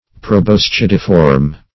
Search Result for " proboscidiform" : The Collaborative International Dictionary of English v.0.48: Proboscidiform \Pro`bos*cid"i*form\, a. Having the form or uses of a proboscis; as, a proboscidiform mouth.